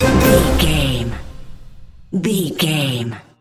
Uplifting
Lydian
flute
oboe
strings
orchestra
cello
double bass
percussion
silly
goofy
comical
cheerful
perky
Light hearted
quirky